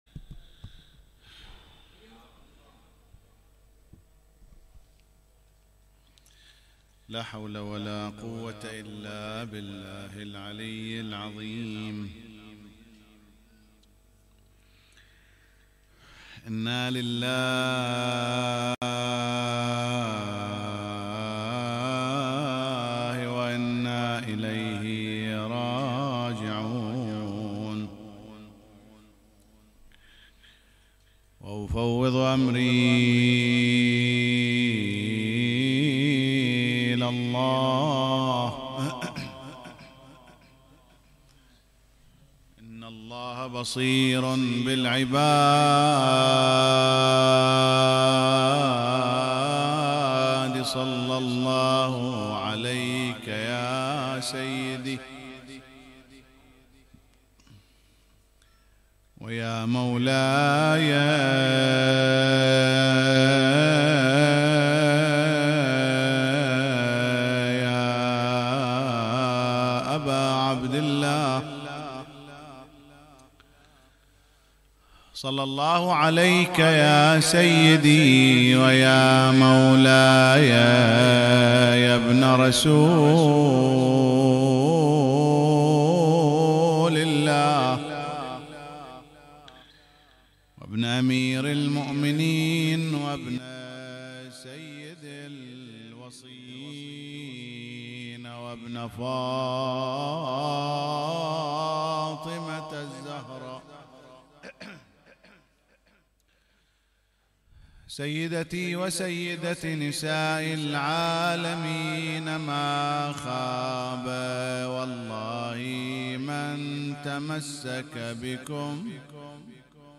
مجلس العزاء